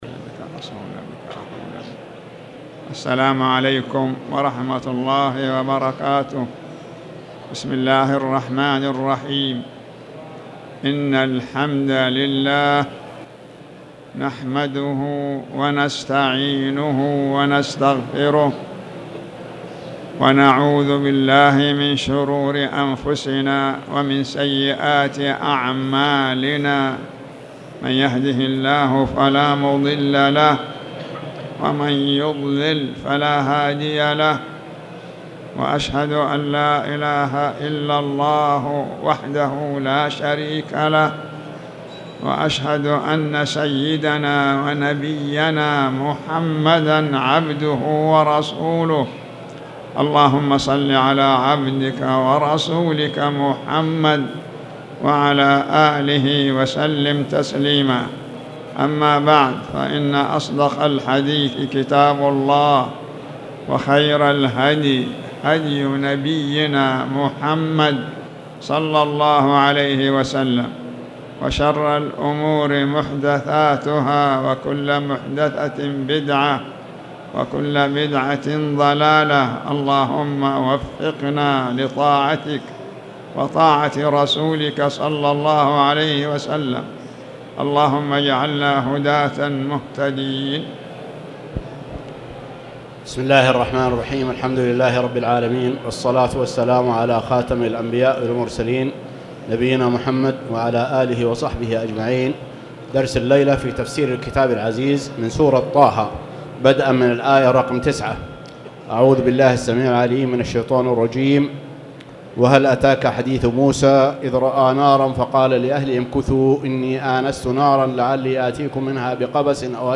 تاريخ النشر ٢٥ جمادى الأولى ١٤٣٨ هـ المكان: المسجد الحرام الشيخ